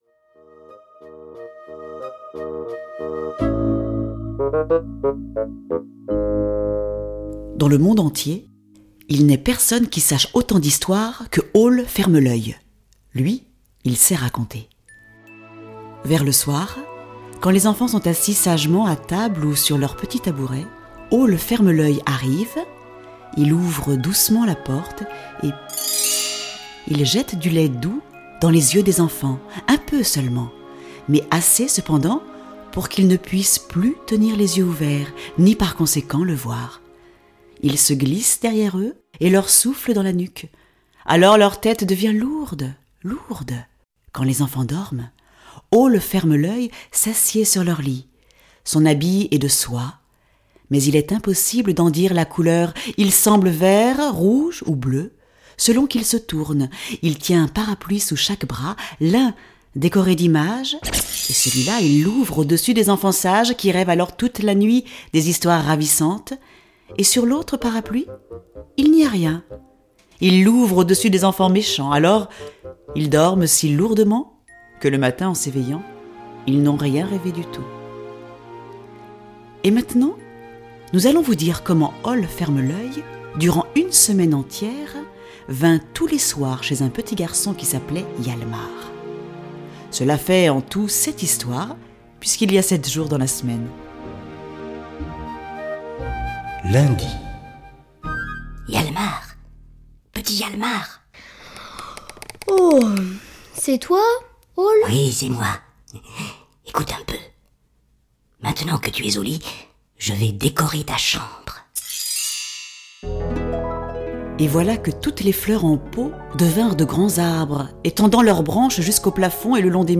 je découvre un extrait